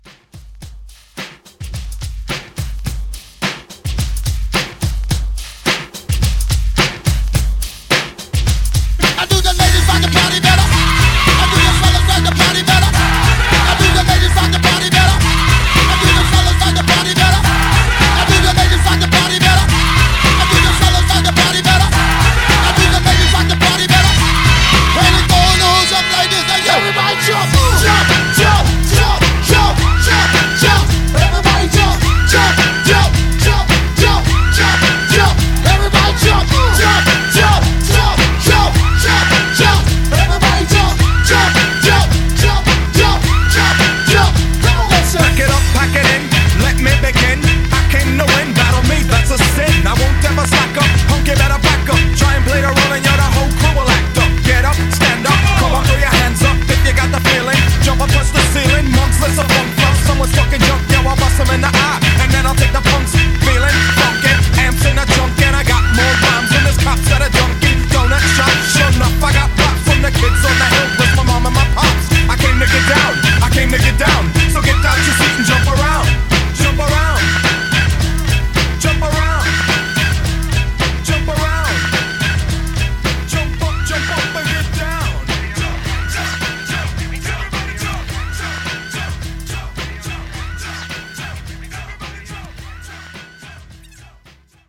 Genre: TOP40
Clean BPM: 106 Time